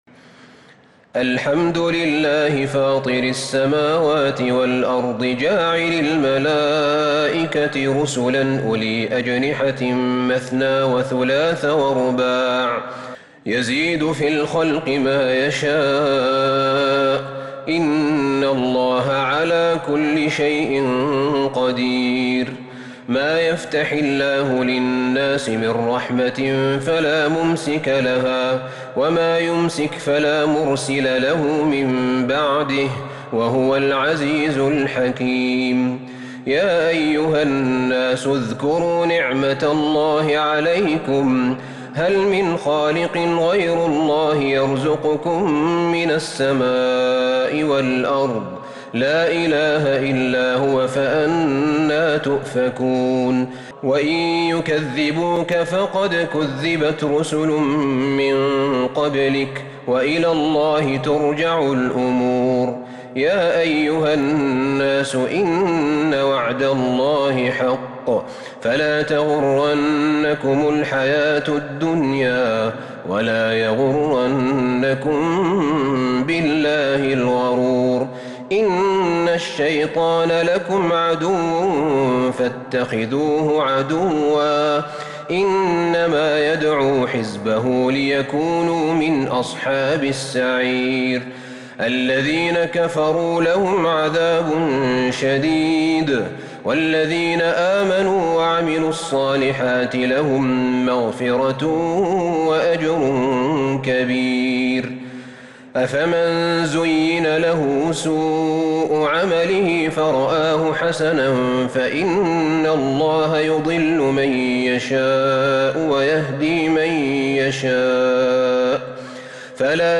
سورة فاطر كاملة من تراويح الحرم النبوي 1442هـ > مصحف تراويح الحرم النبوي عام 1442هـ > المصحف - تلاوات الحرمين